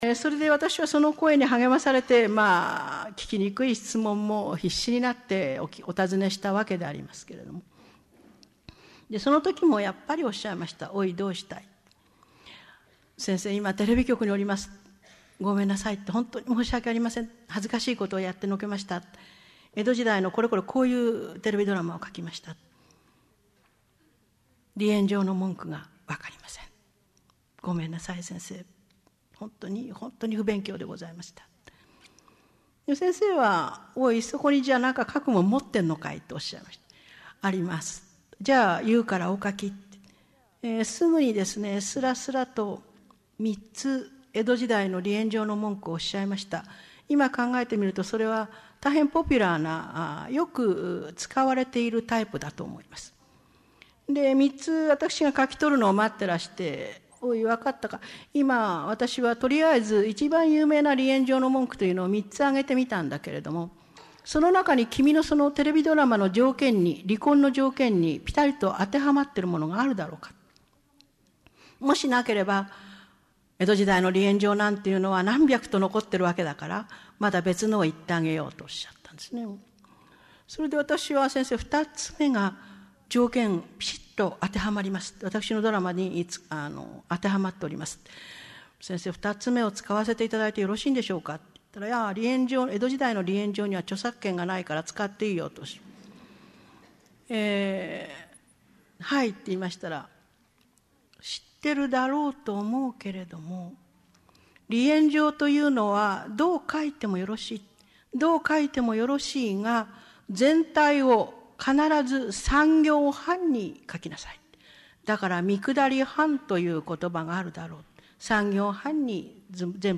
名だたる文筆家が登場する、文藝春秋の文化講演会。
（2000年 高松市 梅花短期大学五十周年記念講演会 文藝春秋文化講演会 講演原題「私の時代小説」より）